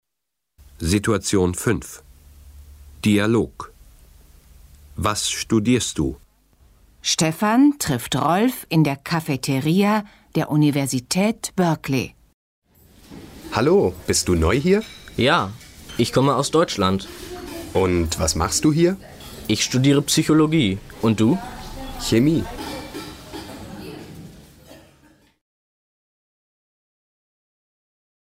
Situation 5 – Dialog: Was studierst du? (455.0K)